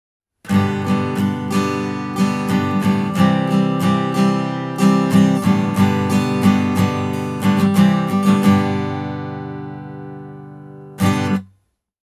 Soundia ja soittodynamiikan toistoa voi kuvailla vain ylistyssanoilla, niin upeasti, kauniisti ja avoimesti SE Angelus Standard soi.
Tältä Angelus kuulostaa Zoom Q2HD:llä äänitettynä:
SE Angelus – plektralla – Q2HD
+ avoin ja dynaaminen soundi